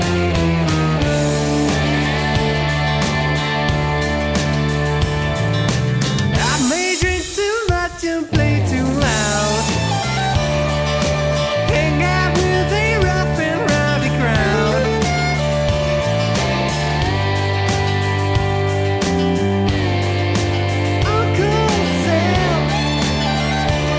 Cut Down Country (Male) 3:14 Buy £1.50